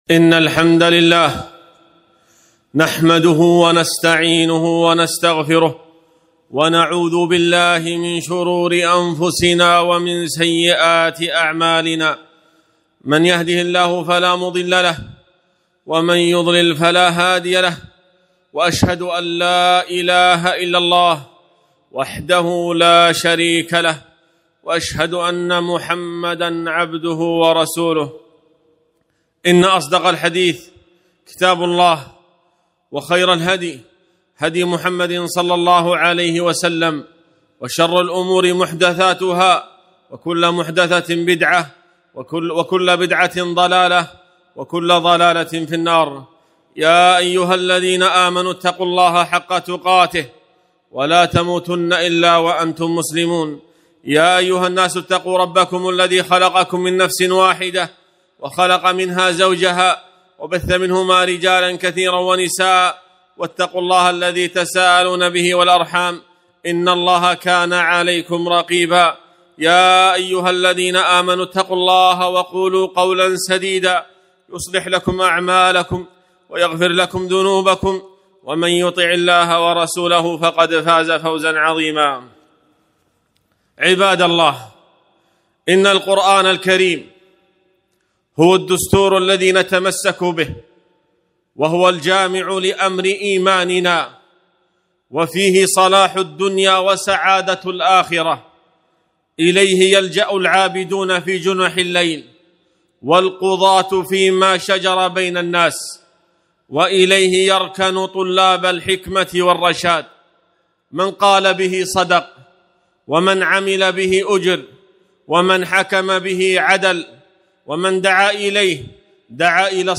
خطبة - التمسك بالقرآن هو سبيل العزة